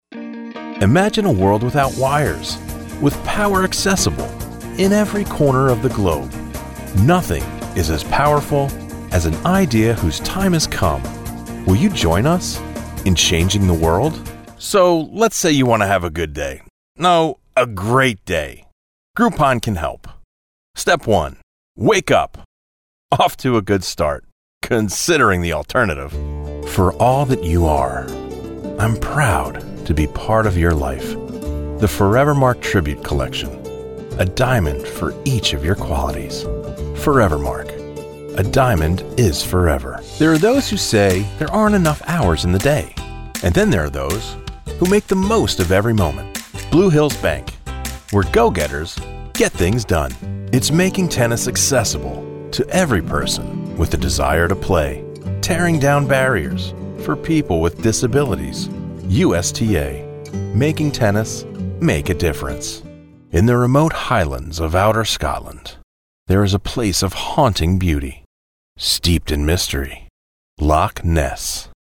American Voice Over Talent
Adult (30-50) | Older Sound (50+)
0616VO_Reel.mp3